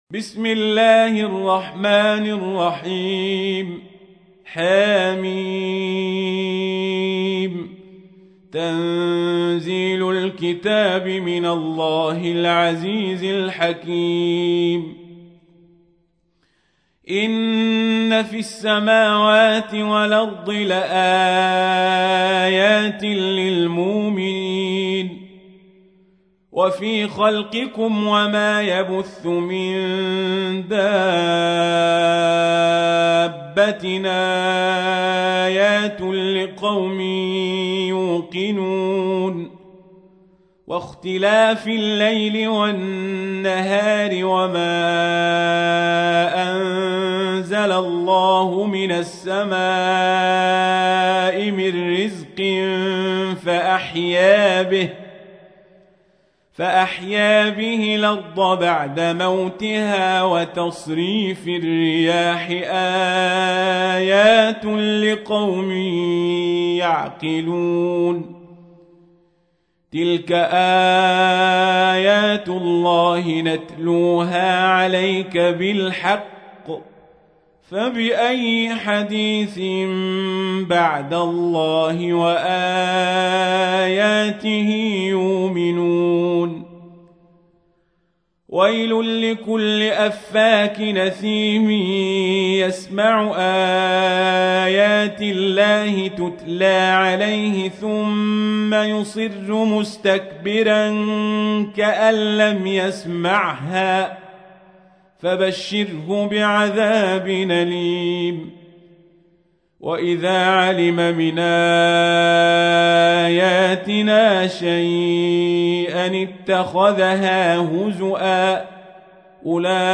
تحميل : 45. سورة الجاثية / القارئ القزابري / القرآن الكريم / موقع يا حسين